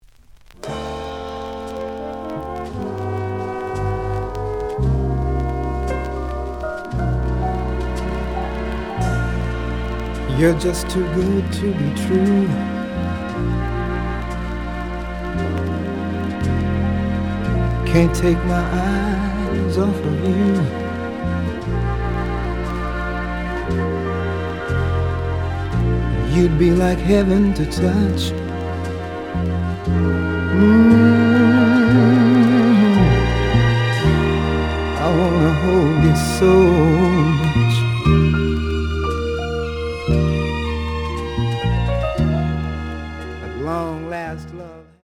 The audio sample is recorded from the actual item.
●Genre: Soul, 60's Soul
Edge warp.